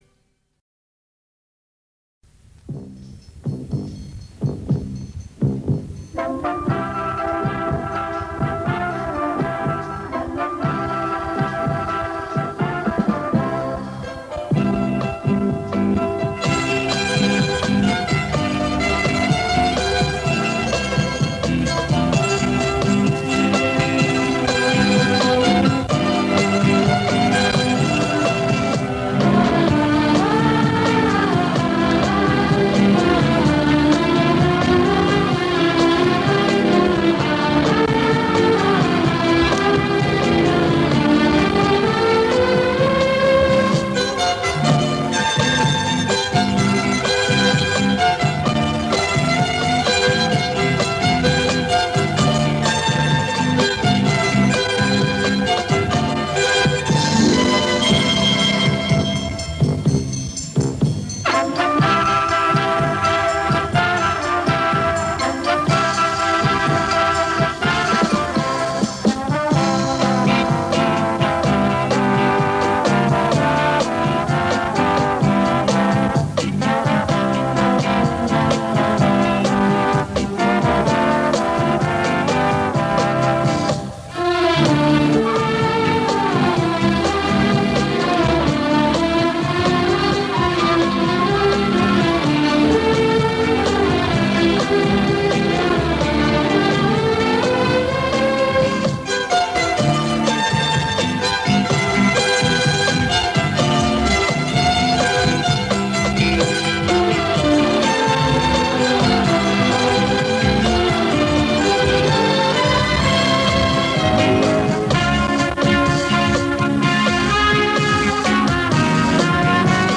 Так он выложил, только качество плохое.